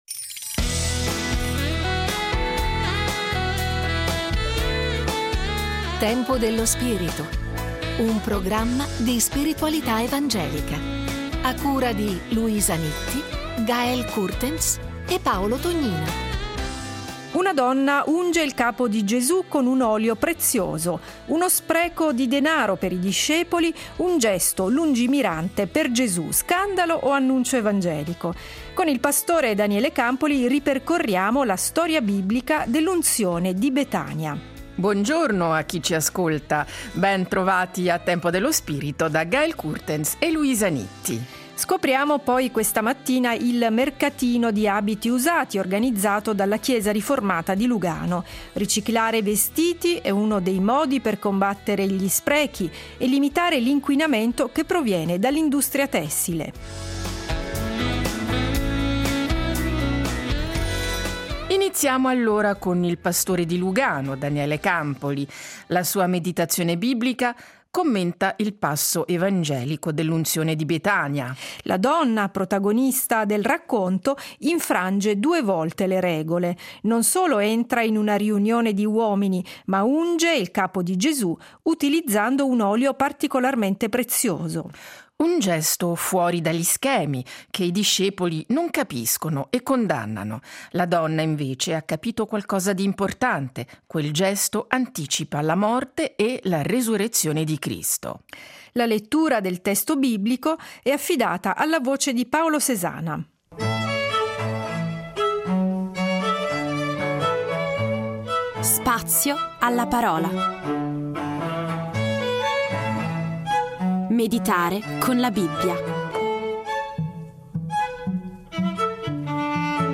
La meditazione biblica